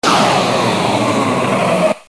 Sortie vapeur
Son - son de vapeur s'échappant sous pression.